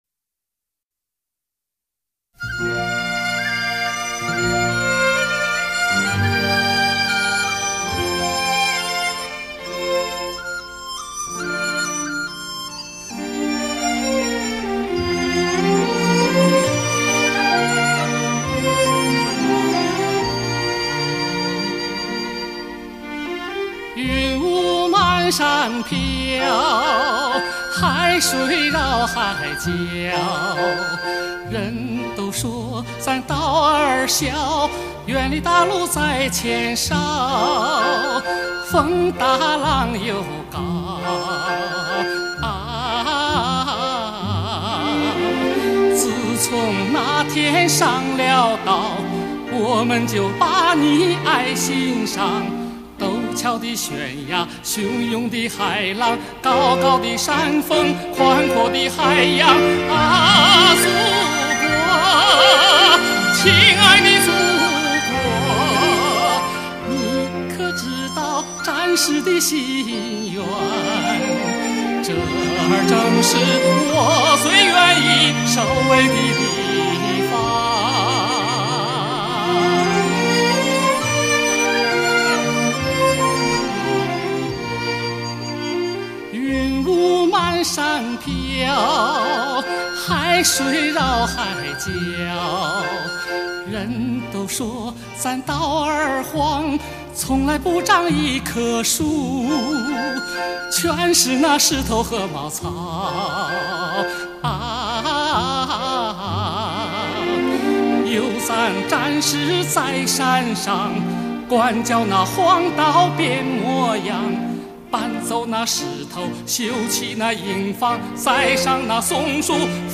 专辑风格：中国传统民歌